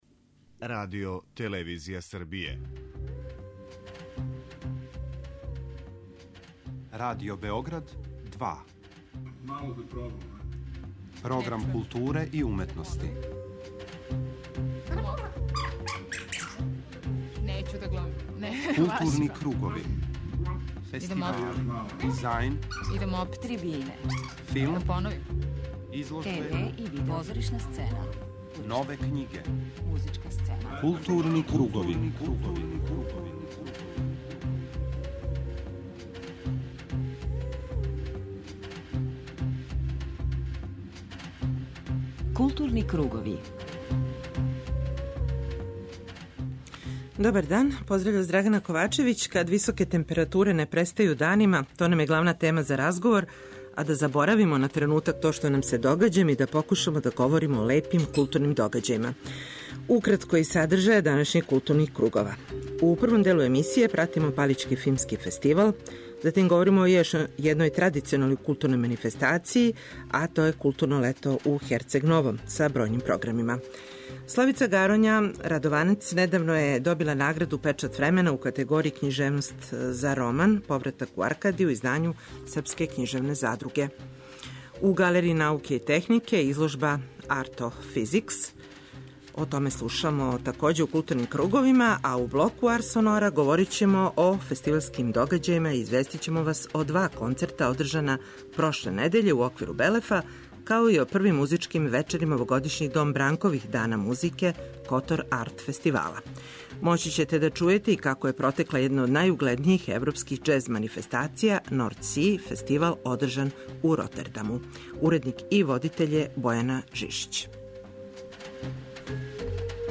преузми : 53.80 MB Културни кругови Autor: Група аутора Централна културно-уметничка емисија Радио Београда 2.